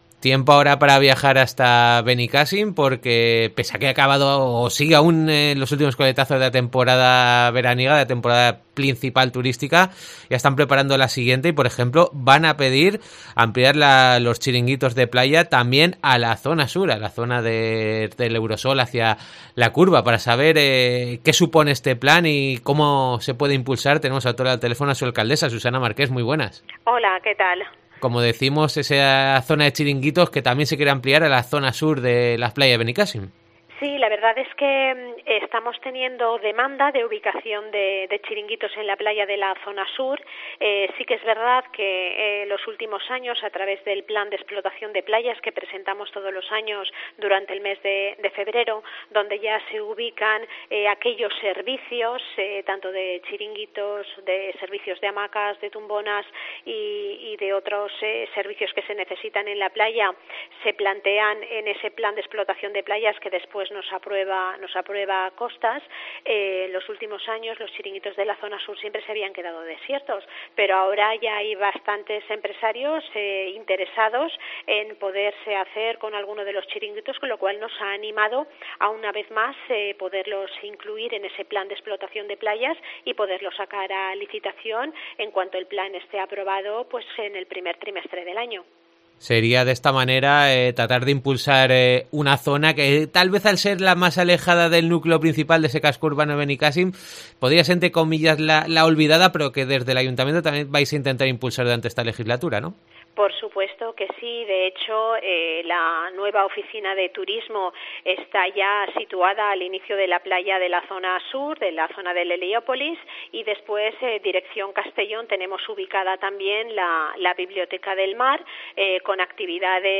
Entrevista a Susana Marqués, alcaldesa de Benicàssim